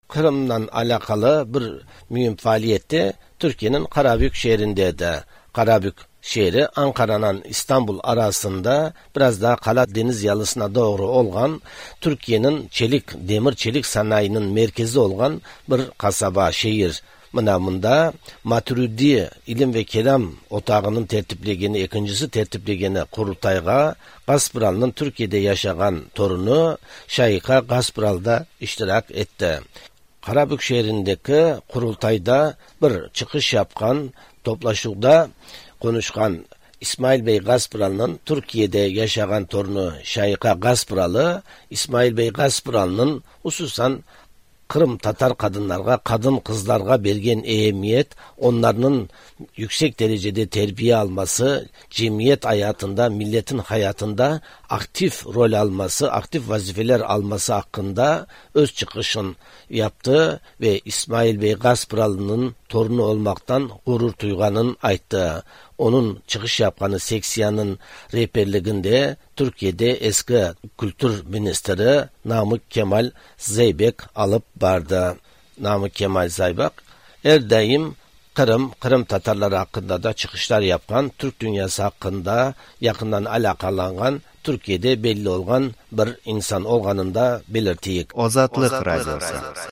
Төркиянең Карабүк шәһәрендә Матуриди Ясәви Отагы (Maturidi Yesevi Otağı) вакыфының гыйлми һәм әдәби эзләнүләр оешмасы корылтаенда